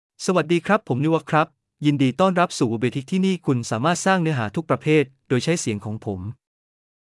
MaleThai (Thailand)
Niwat — Male Thai AI voice
Niwat is a male AI voice for Thai (Thailand).
Voice sample
Niwat delivers clear pronunciation with authentic Thailand Thai intonation, making your content sound professionally produced.